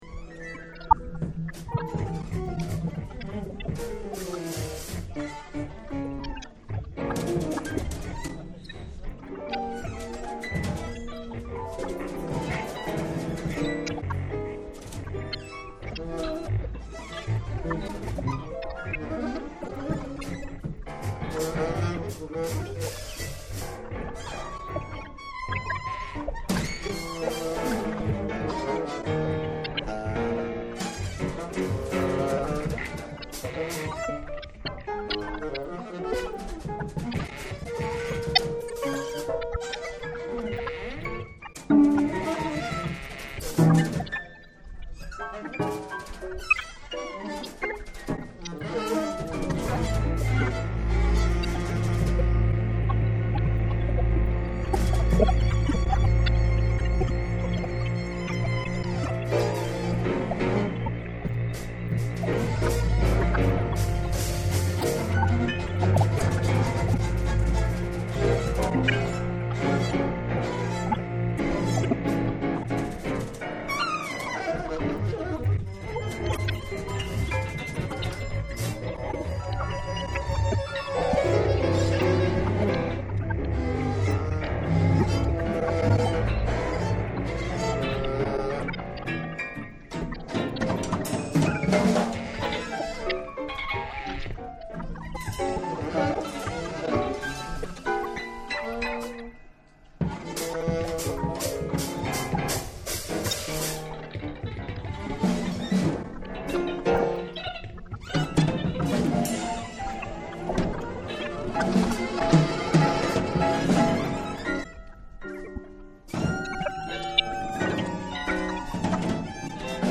26.3.09/ die frei improvisierende szene norddeutschlands
traf sich zur ekstatischen soundorgie
im bunker ulmenwall/ bielefeld
am piano
weitere 15 gastmusiker anwesend
obertonsänger